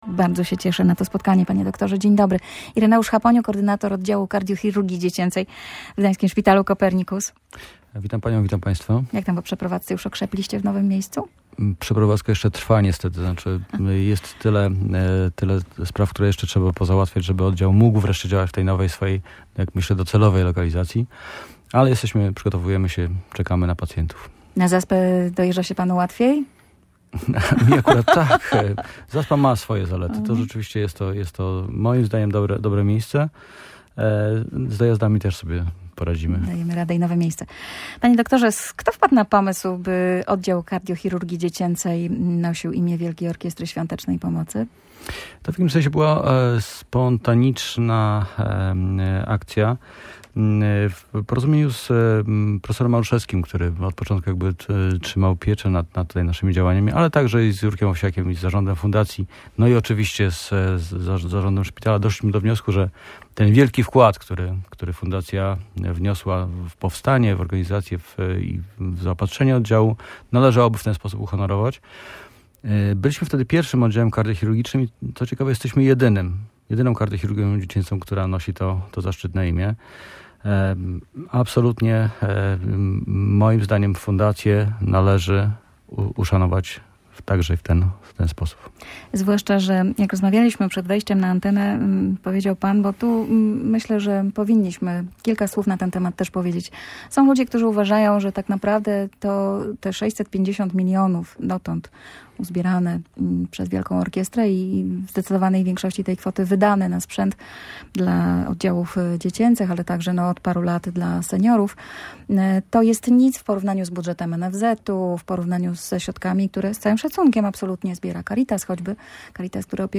Goszcząc w Radiu Gdańsk opowiadał o leczeniu serc u dzieci i nowej siedzibie oddziału.